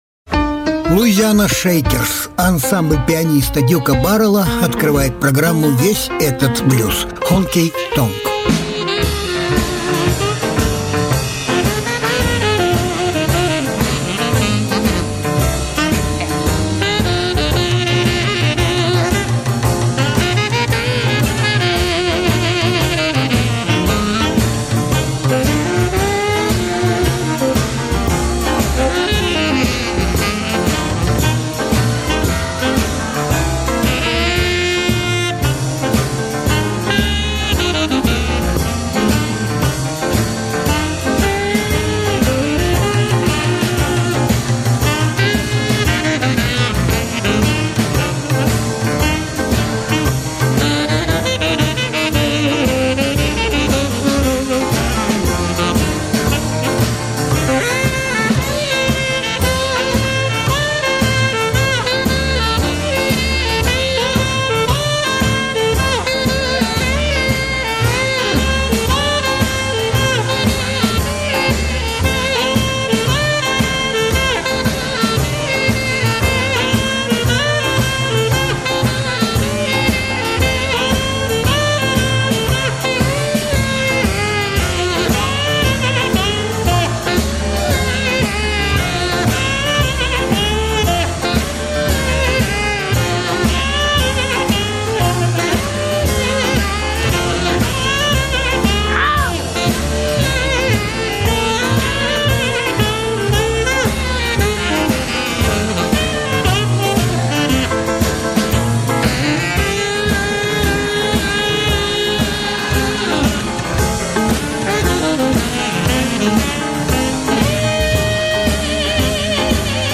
Жанр: Блюзы